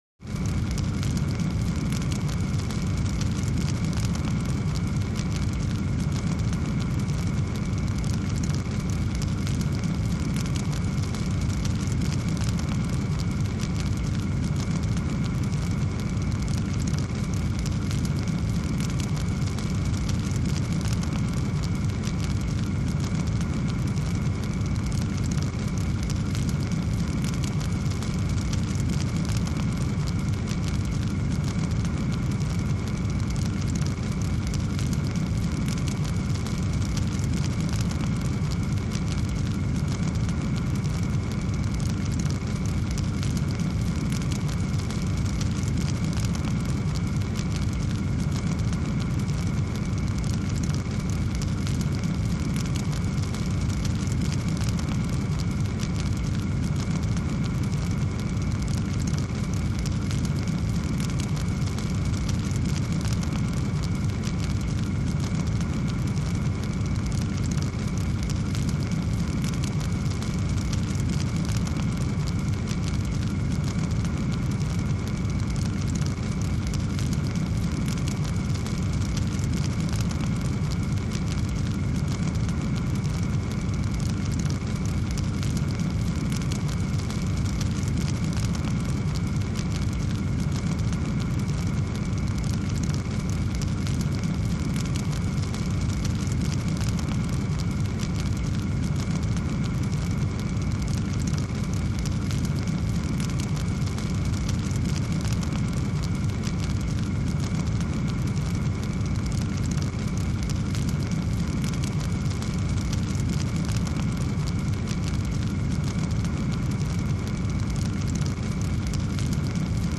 Large W/Crackles | Sneak On The Lot
Fire; Large, Roar And Hiss With Crackles. Fire Burn.